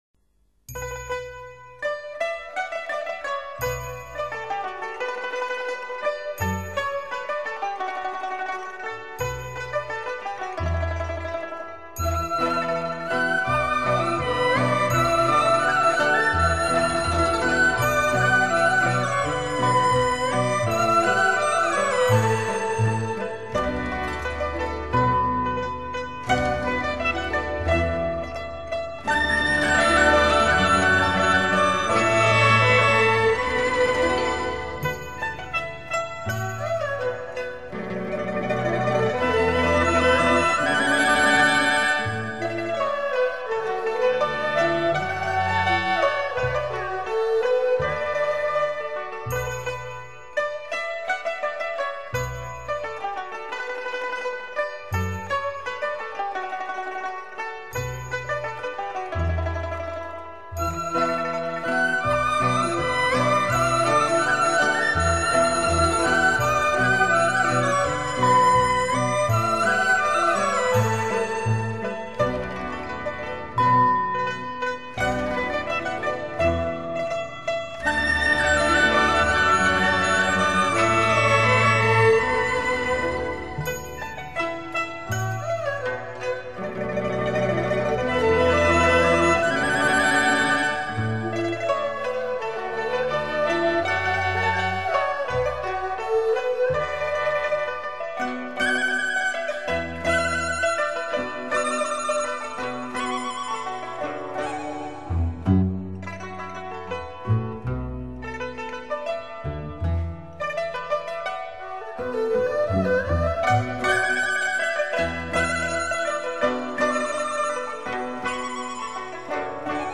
本专辑均以长安的历史传说或风土人情为素材创作而成，具有浓郁的地域色彩。